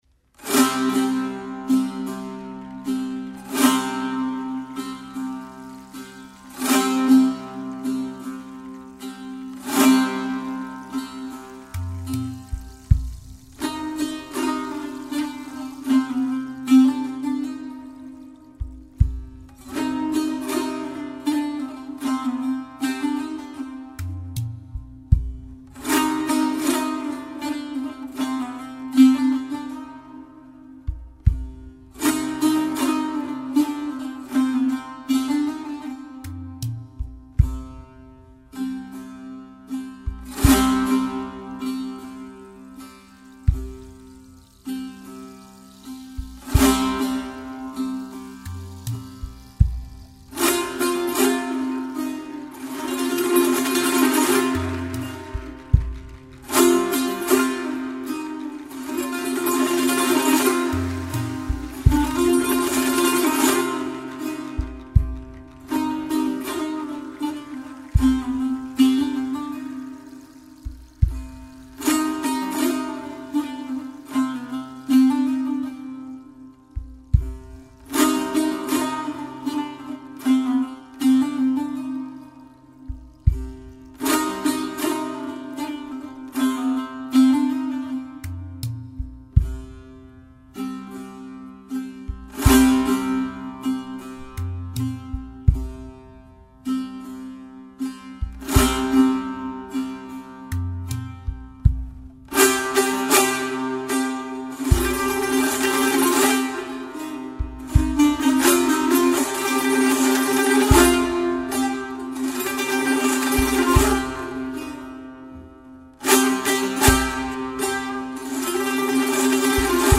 قطعه کوتاه تکنوازی تنبور